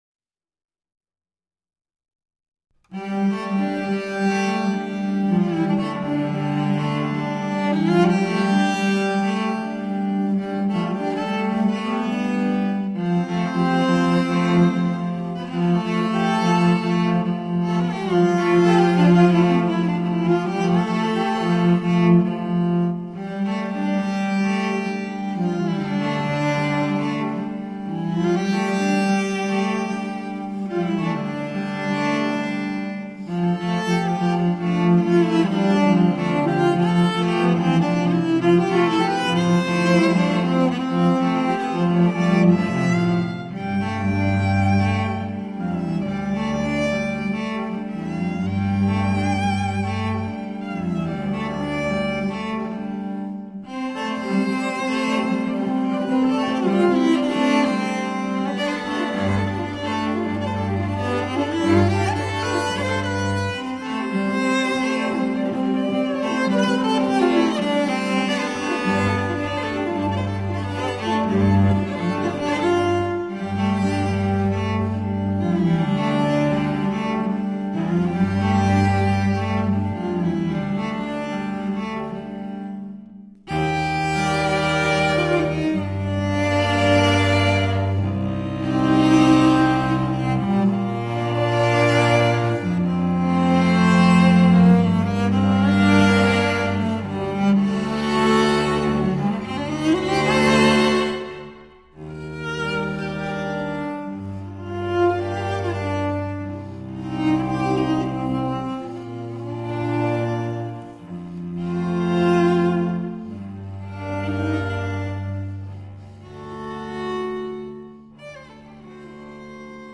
is scored for five cellos